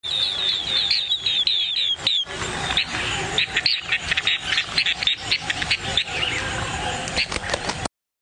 绿喉蜂虎鸣叫声
绿喉蜂虎鸟叫声户外采集 鹤鸵叫声 食火鸟鸣叫声 人气鸟类 1 云雀 2 黄雀 3 画眉 4 丹顶鹤 5 白腰文鸟 最新鸟叫声 野外画眉母鸟叫声 打口提性 悦耳动听画眉母鸟叫声 9s嘹亮画眉母鸟鸣叫声 公眉必应 野生母画眉鸟叫声 母画眉发情叫声 画眉鸟叫声母音欣赏